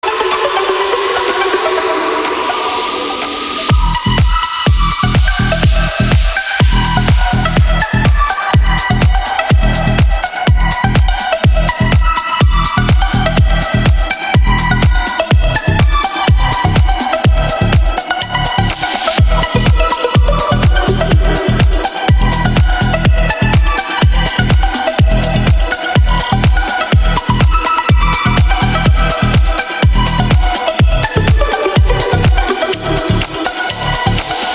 • Качество: 320, Stereo
без слов
Tech House
progressive house
Melodic